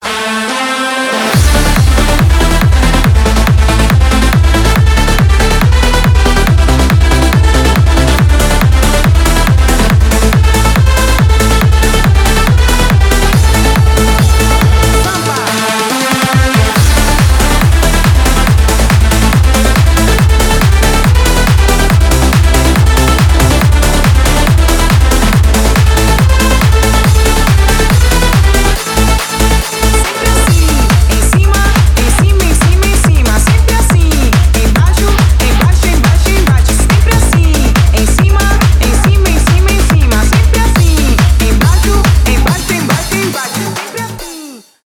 техно , edm
клубные